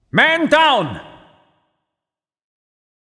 wolf_mandown.wav